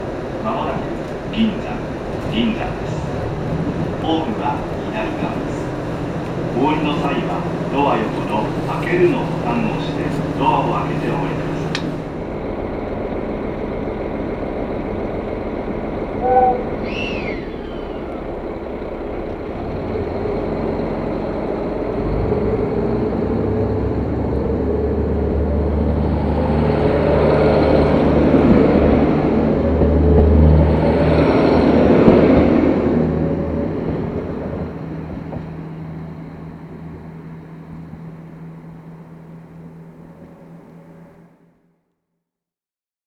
函館本線の音の旅｜キハ201系ニセコライナー銀山駅到着アナウンスと走行音
函館本線・山線区間でキハ201系ニセコライナーに乗車し、銀山駅到着時のアナウンスと走り去る音を収録。然別駅から反対列車に乗車する貴重な記録で、この区間でキハ201系を体験できる貴重な音鉄素材です。